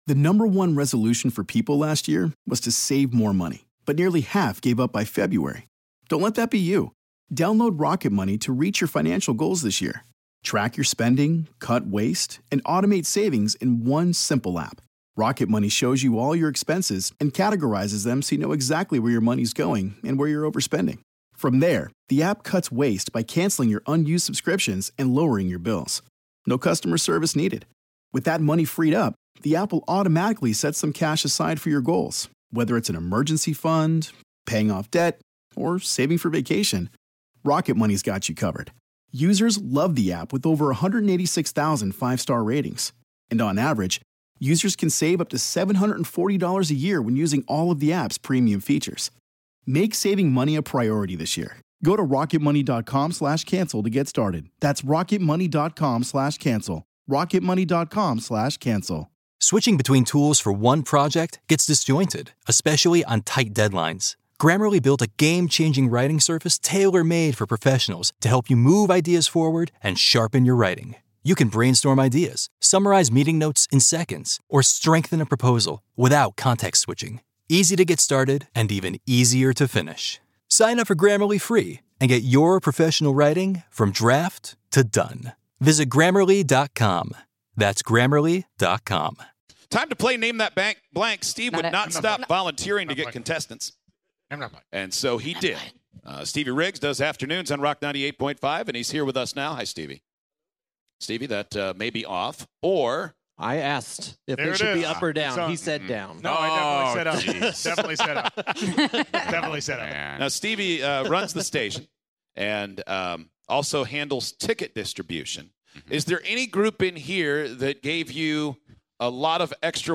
On today's show, we had two very special contestants for Name That Blank during our Kokomo Live Show.